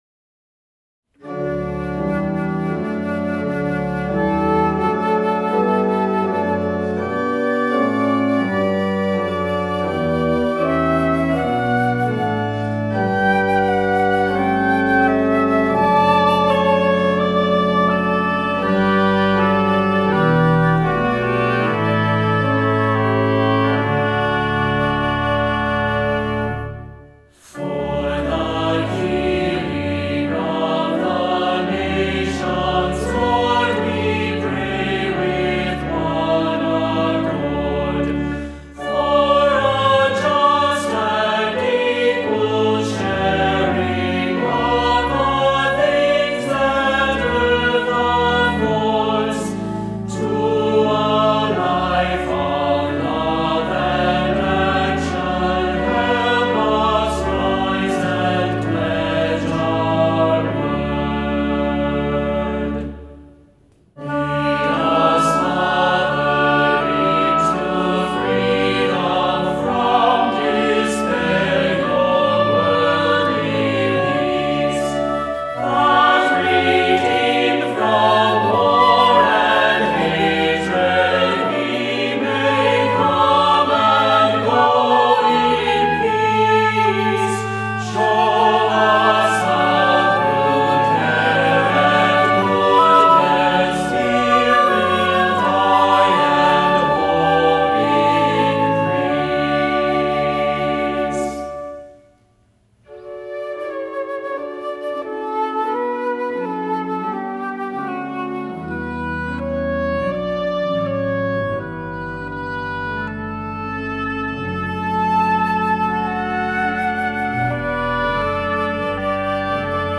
Voicing: "SATB, Descant, Assembly"